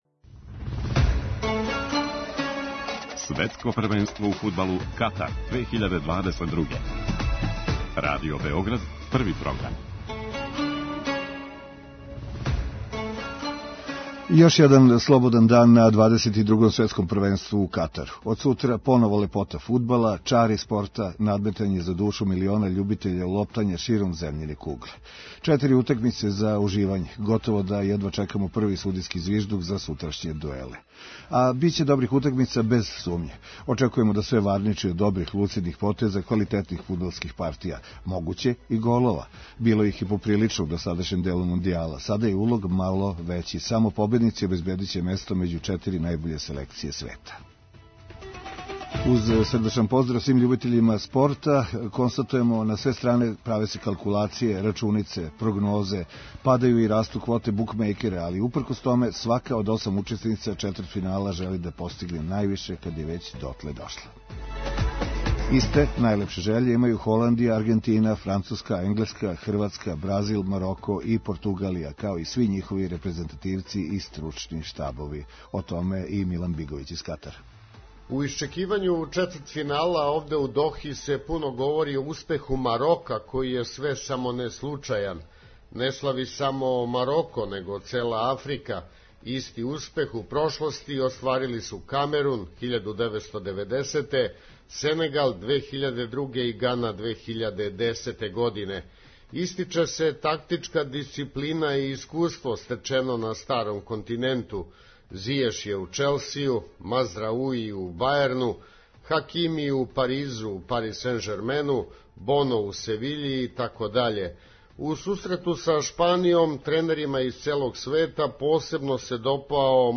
Наши репортери са лица места